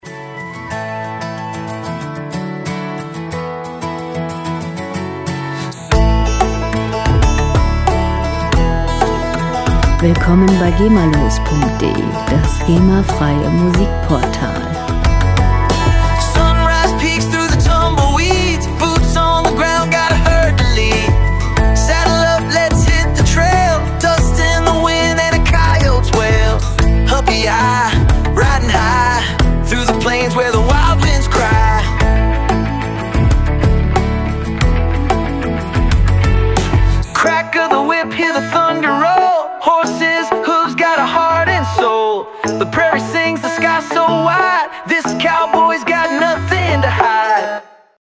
• Country Pop
ein energiegeladener Country Pop Hit